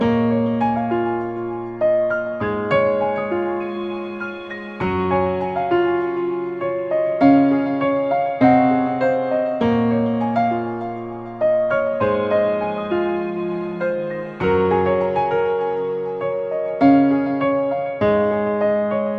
RNB钢琴110bpm Dmajor
Tag: 110 bpm RnB Loops Piano Loops 1.47 MB wav Key : D